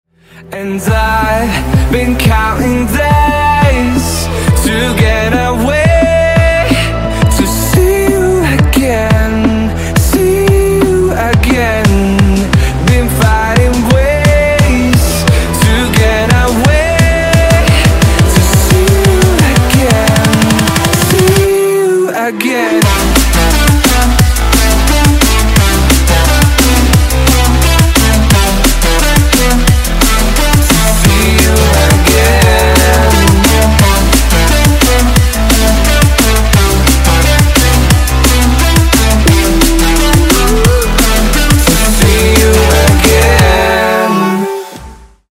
dubstep